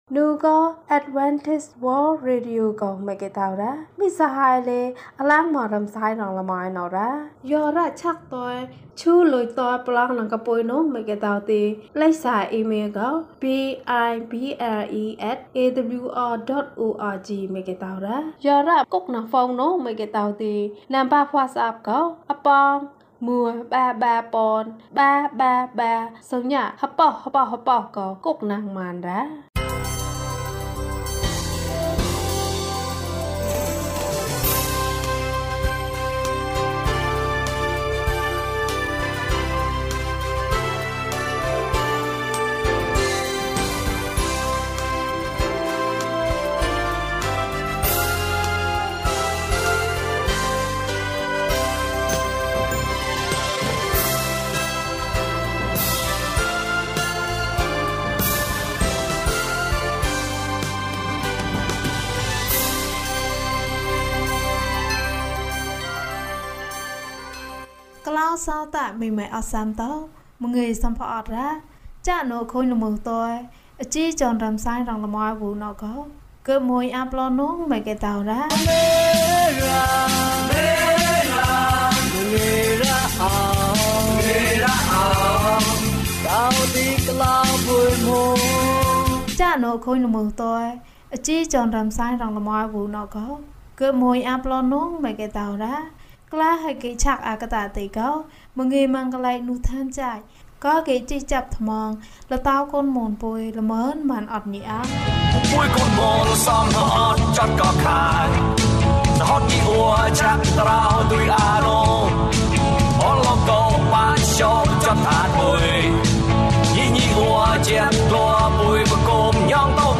သူသည် ကြီးမြတ်၏။ ကျန်းမာခြင်းအကြောင်းအရာ။ ဓမ္မသီချင်း။ တရားဒေသနာ။